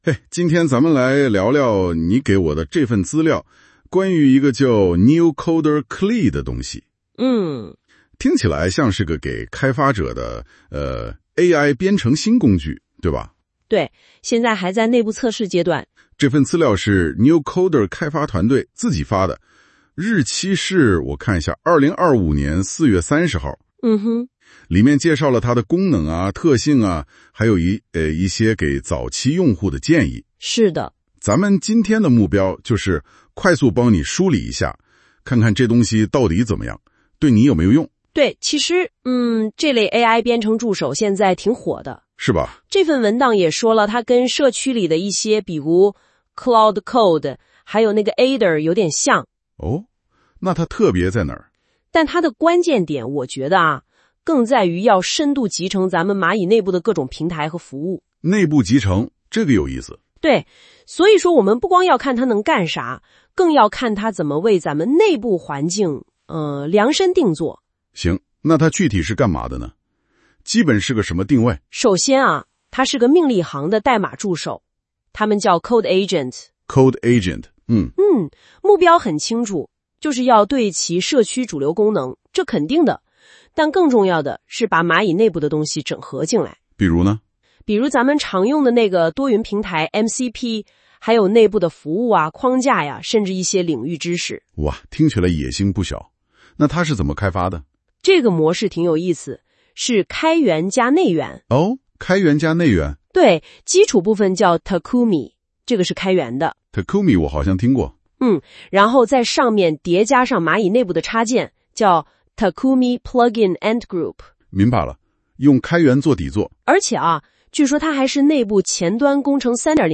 2、Notebook LM 支持生成中文等 50 国语言的播客，早上看到时顺手为今天内部的 NeoCoder Cli（Takumi 内部版）生成了一份播客，见